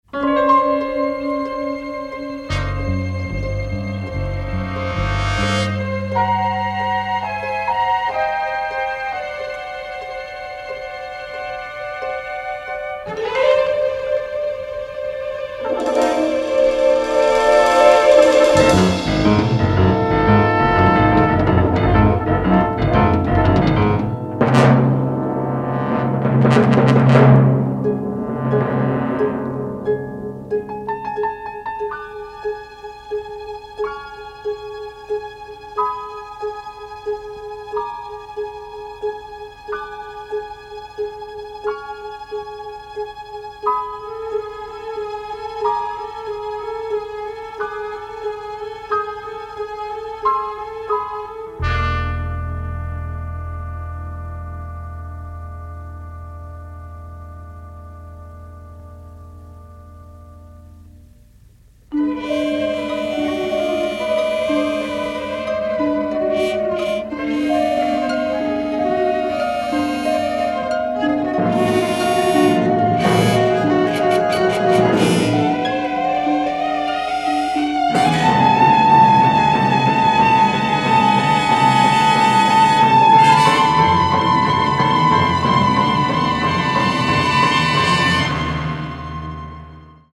original score tracks from 70s era cop/detective series